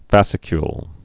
(făsĭ-kyl)